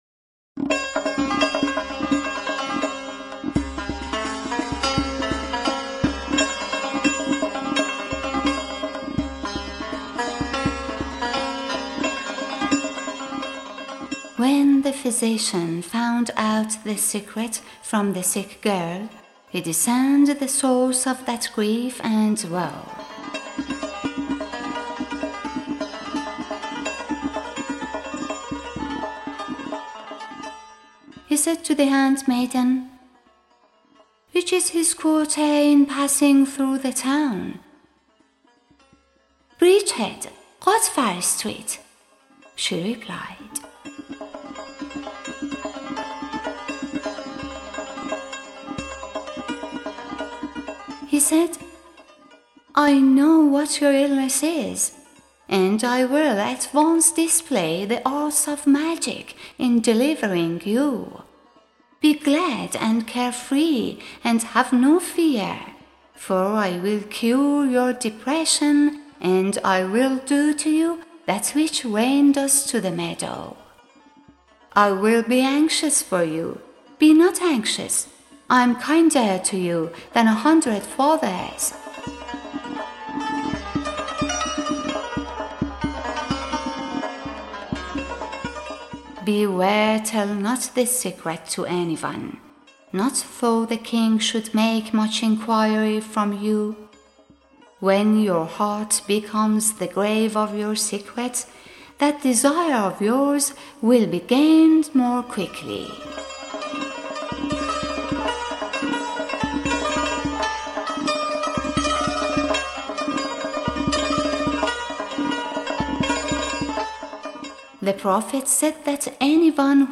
Music by: Parviz Meshkatian